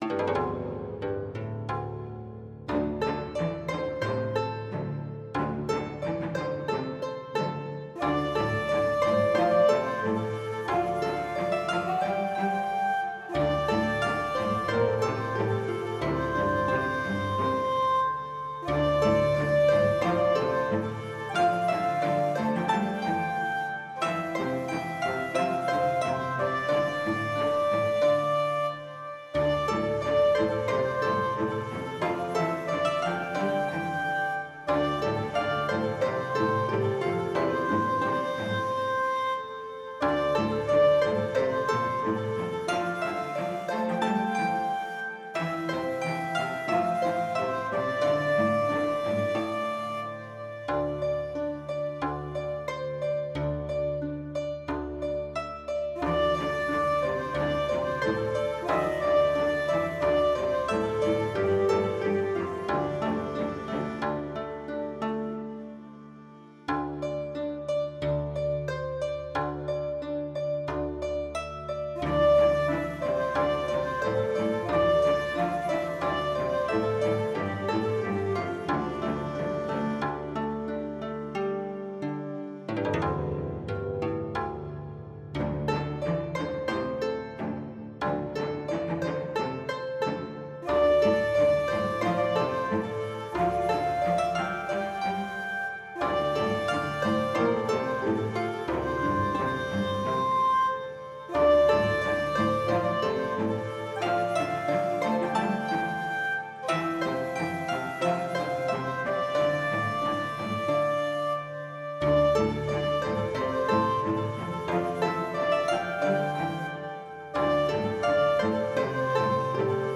When combined with Western instruments, this cello gives us quite an interesting blend. Here we have Shakahuci, Guzheng and Cello.
Lotus-awakening-with-cello.mp3